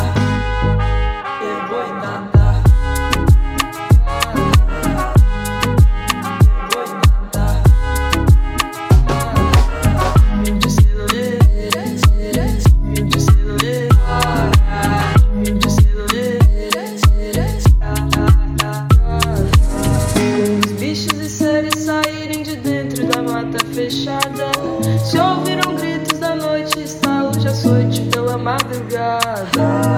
Электроника — это про будущее, которое уже здесь.
Electronic
2025-04-09 Жанр: Электроника Длительность